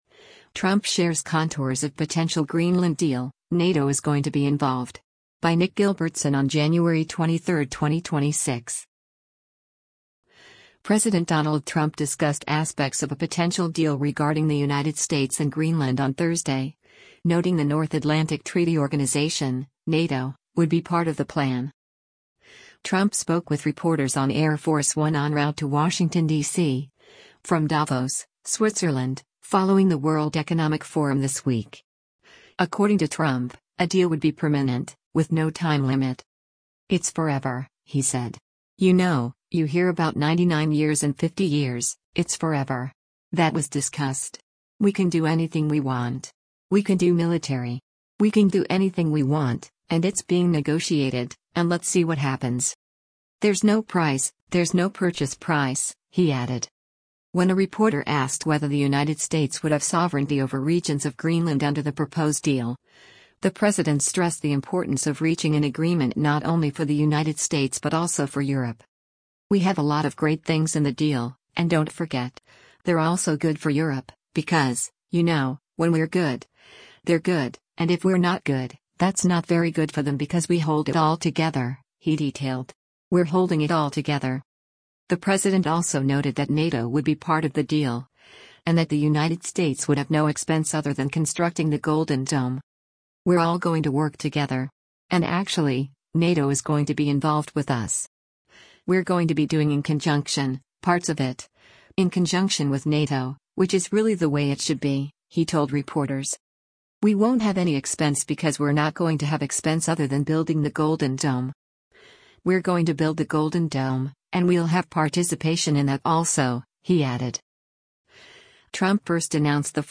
Trump spoke with reporters on Air Force One en route to Washington, DC, from Davos, Switzerland, following the World Economic Forum this week.